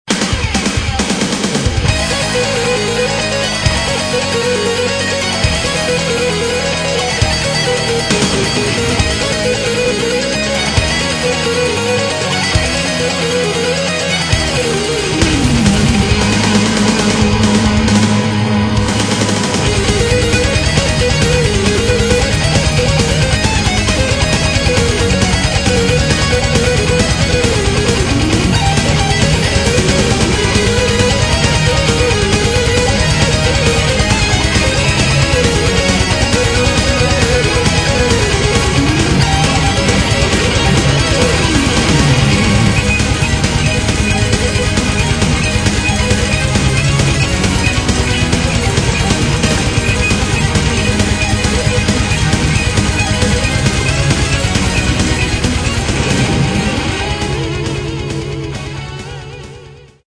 Metal
Неоклассический шедевр!